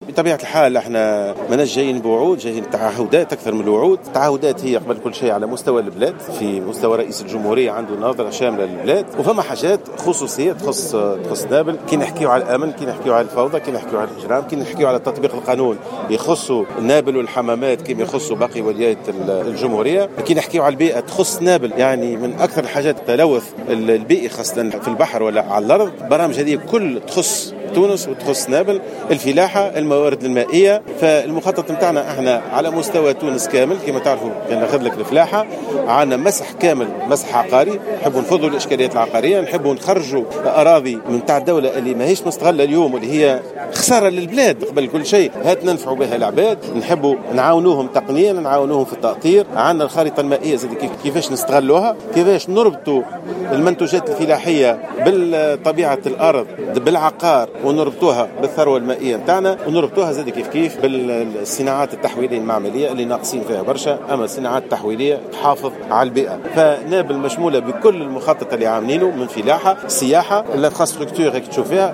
قال مهدي جمعة المترشح للرئاسة في تصريح للجوهرة "اف ام" على هامش حملته الانتخابية في نابل أنه لا يحمل وعودا بل تعهدات في مستوى صلاحيات رئيس الجمهورية.